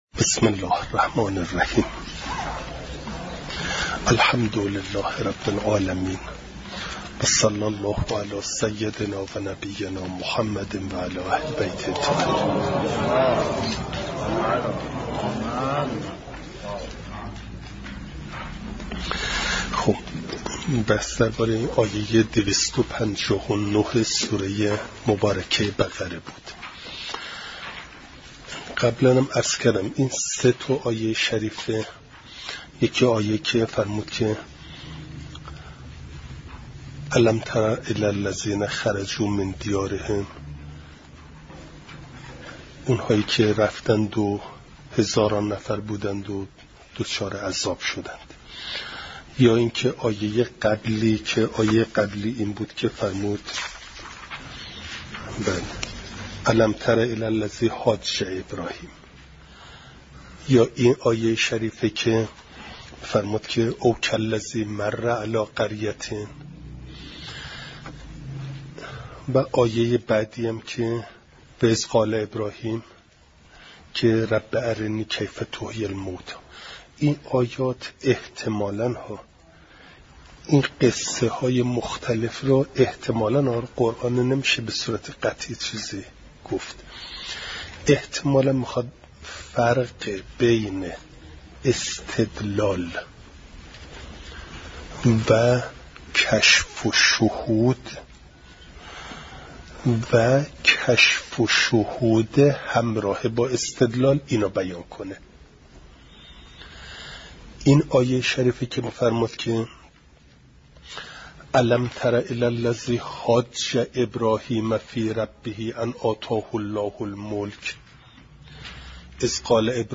فایل صوتی جلسه دویست و سی و پنجم درس تفسیر مجمع البیان